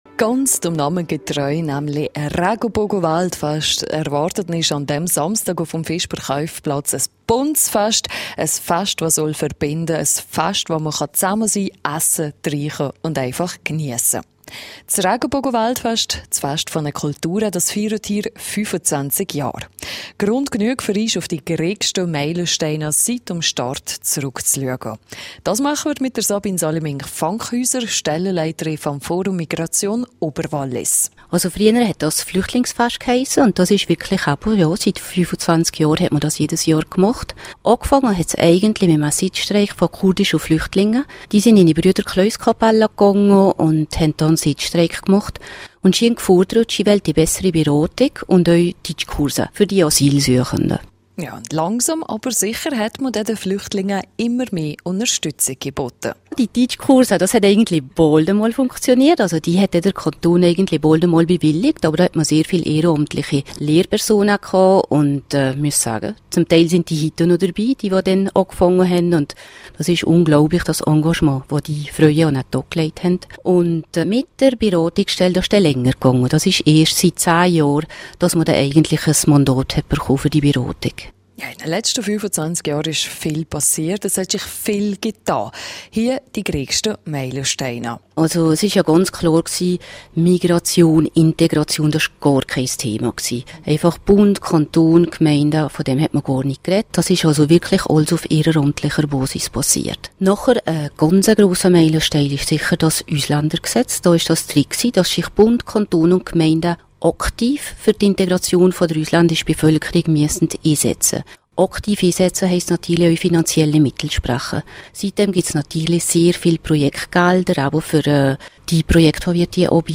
Mit traditionellen Gerichten aus Serbien, Kroatien, Äthiopien, Somalia und Angola./rj Interview zum Thema (Quelle: rro)